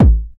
BD BD050.wav